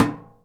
metal_tin_impacts_soft_07.wav